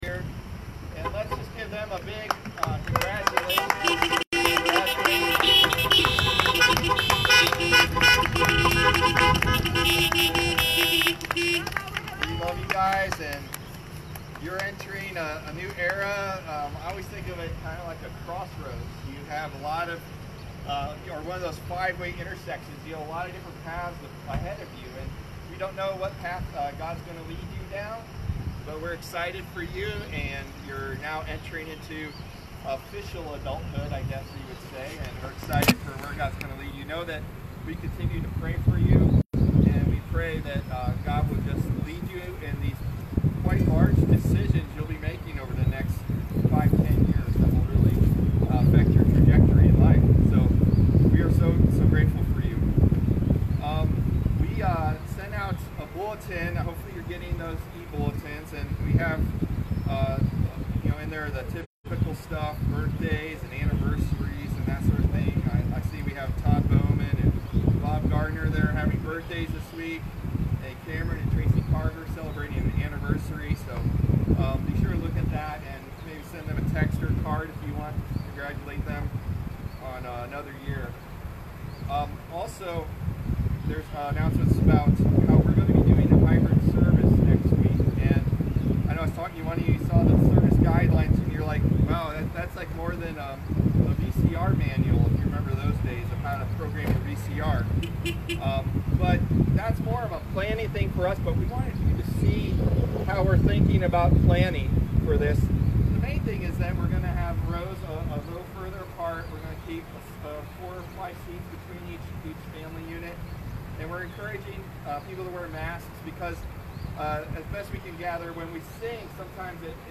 We recorded our drive-in church service on Facebook Live.